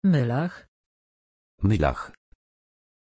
Pronunția numelui Mylah
pronunciation-mylah-pl.mp3